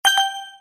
bomb_5.mp3